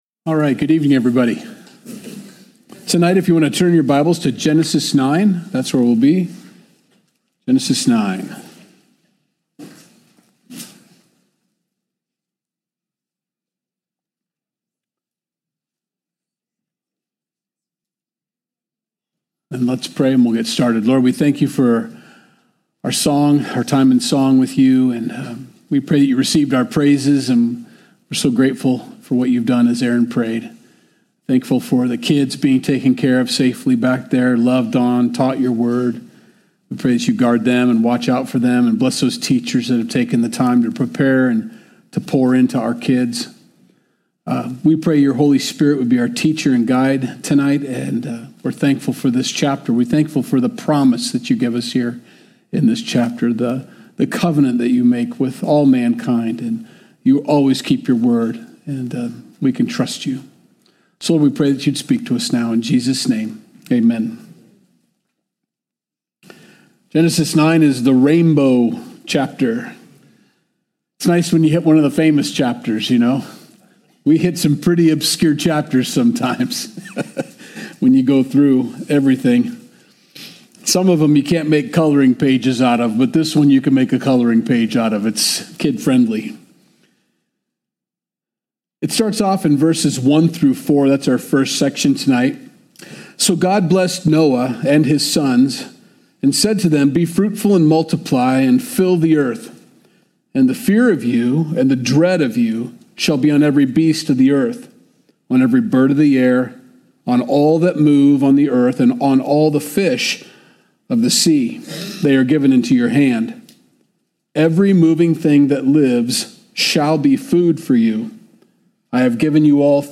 Wednesday Message - January 29th, 2025